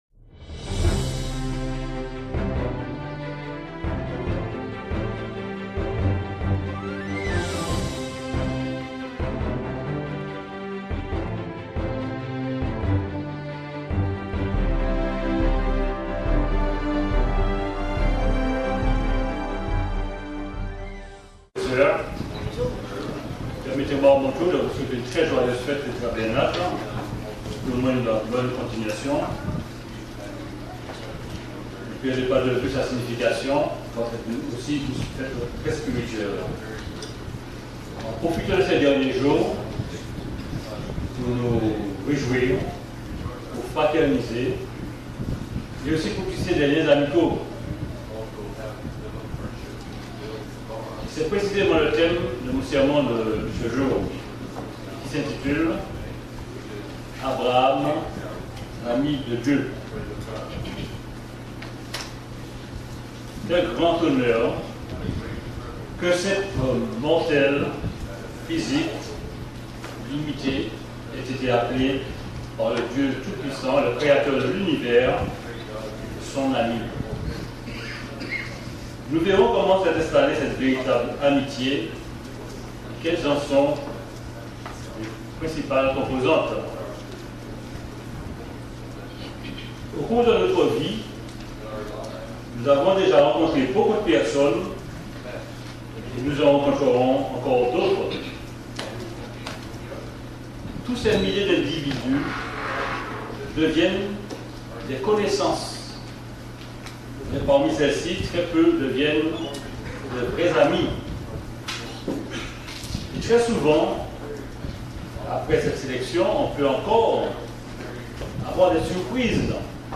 Fête des Tabernacles Abraham